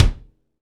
Index of /90_sSampleCDs/Northstar - Drumscapes Roland/KIK_Kicks/KIK_Funk Kicks x
KIK FNK K0LR.wav